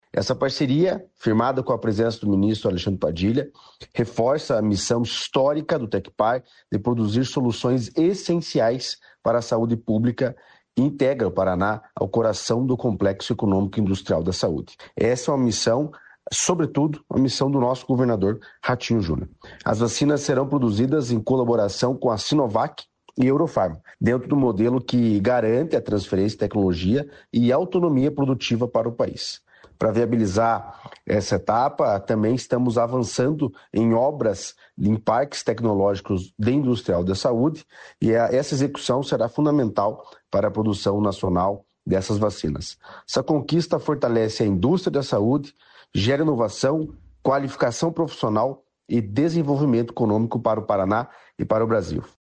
Sonora do diretor-presidente do Tecpar, Eduardo Marafon, sobre parceria com o Ministério da Saúde para fornecer vacinas humanas